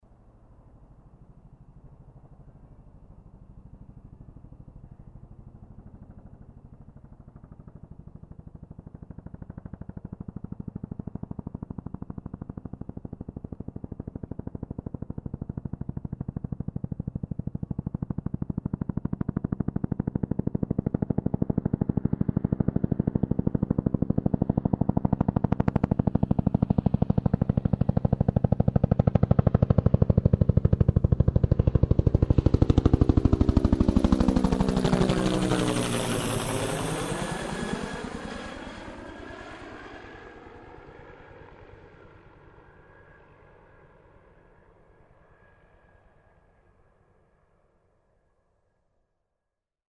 Звуки вертолетов